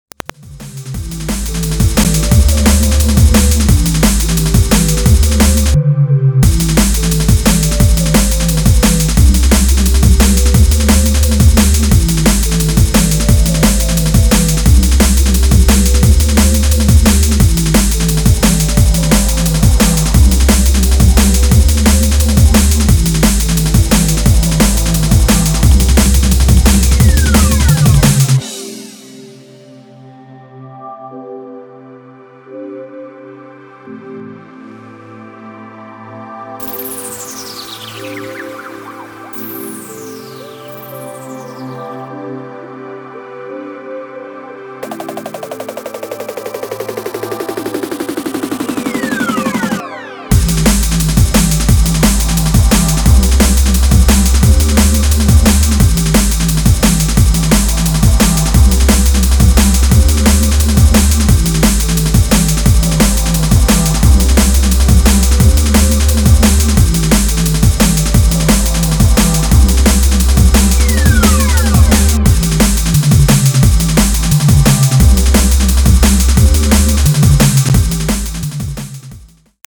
rockt zielstrebig, elegant und leicht zugleich
und dazu mit einer fetten Bassline.